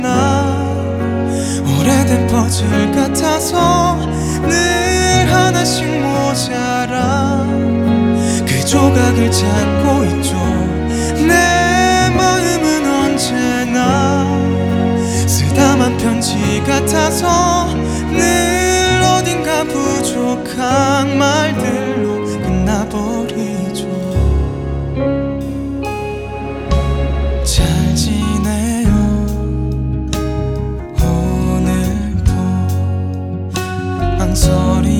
K-Pop
Жанр: Поп музыка / Соундтрэки